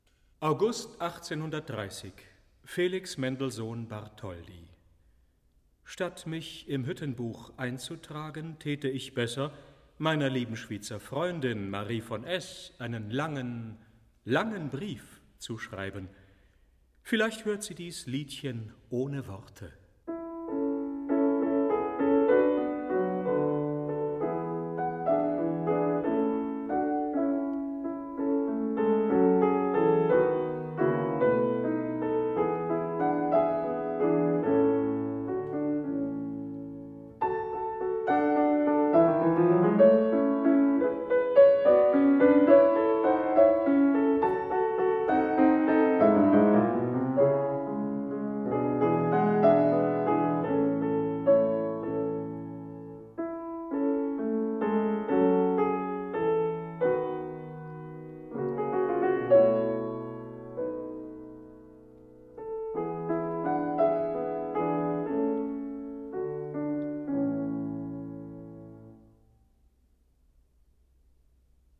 Albert Moeschinger: Felix Mendelssohn, 1830 (piano)